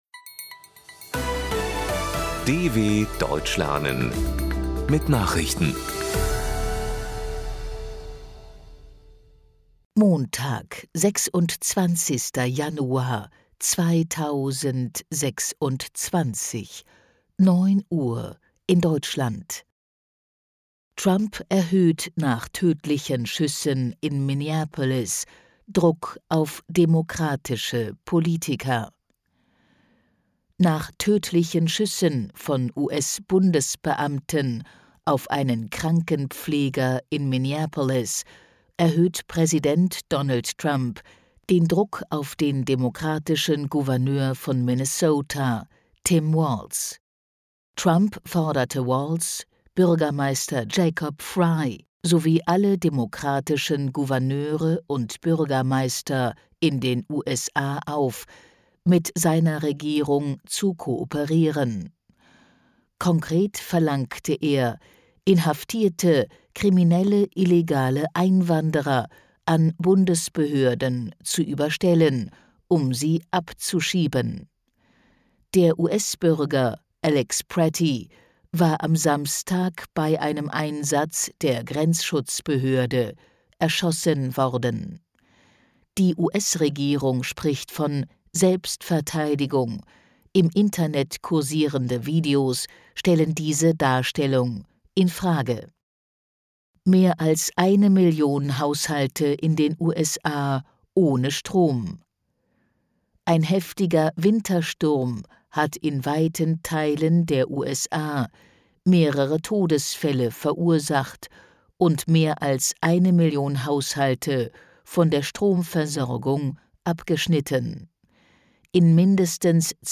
26.01.2026 – Langsam Gesprochene Nachrichten
Trainiere dein Hörverstehen mit den Nachrichten der DW von Montag – als Text und als verständlich gesprochene Audio-Datei.